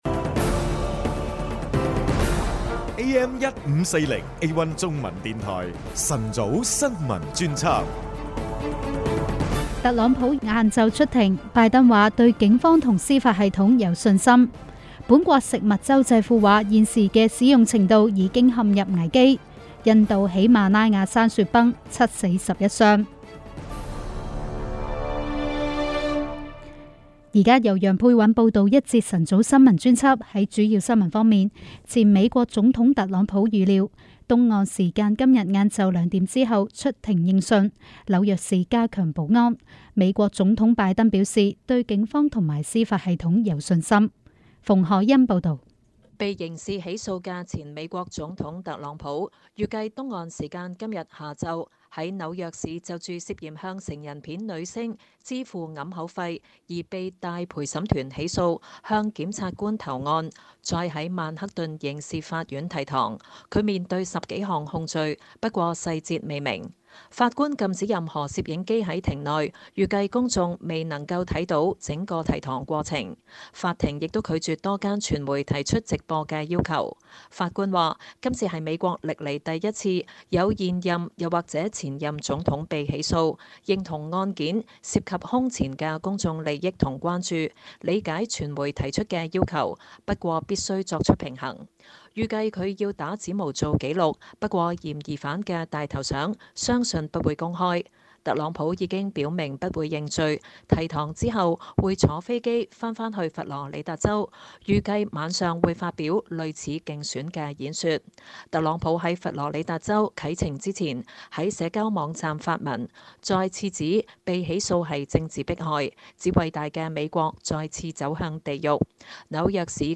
【有聲新聞】星島A1中文電台 晨早新聞專輯